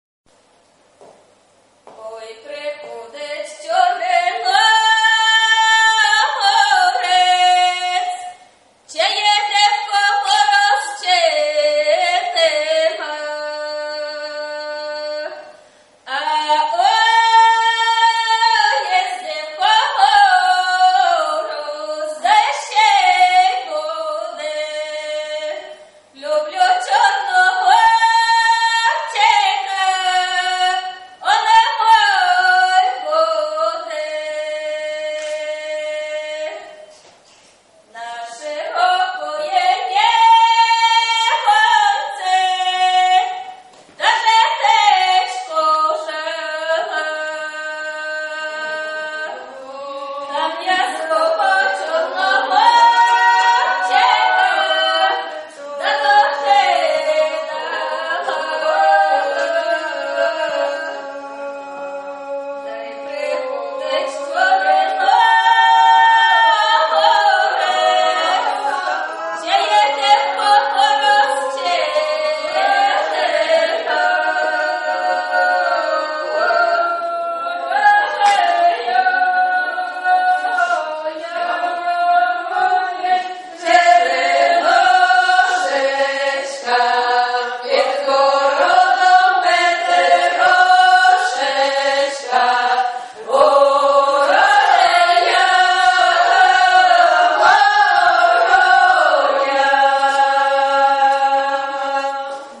Палескія песьні з Варшавы
Песьня "Ору ж я" (выконваюць жыхаркі вёскі Альманы)